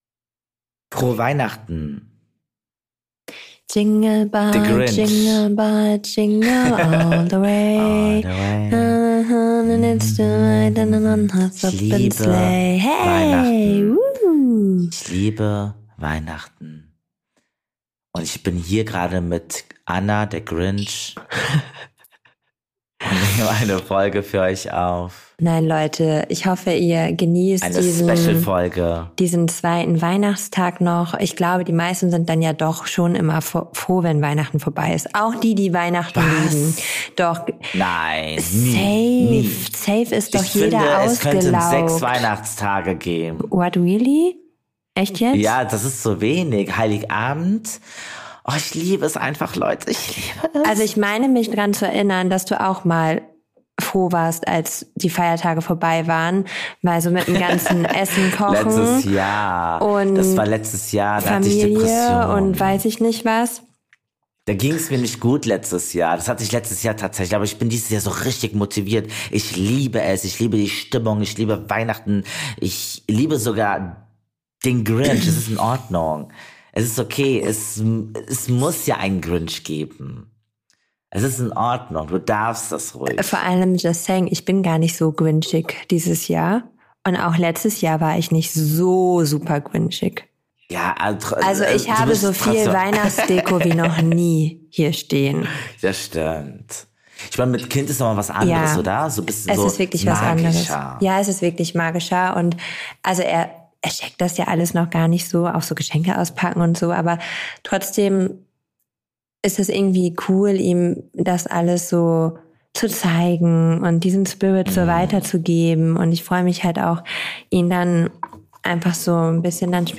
Humorvolle Gespräche